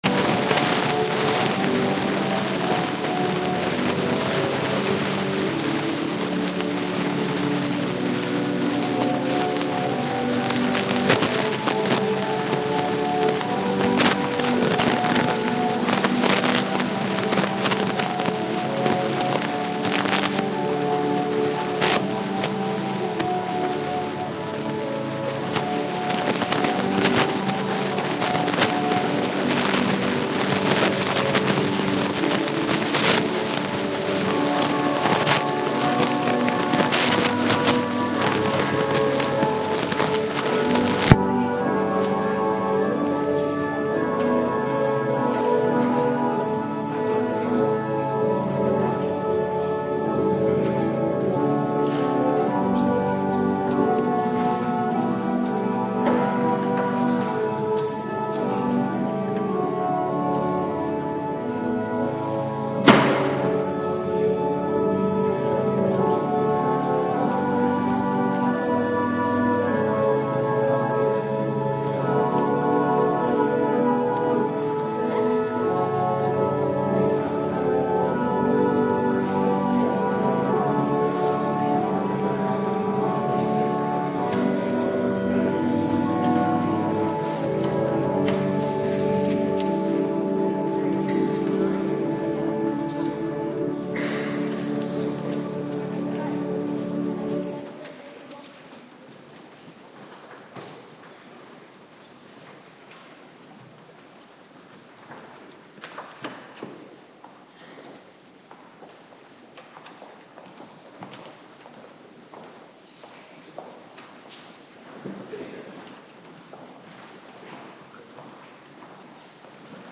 Avonddienst - Cluster 3
Locatie: Hervormde Gemeente Waarder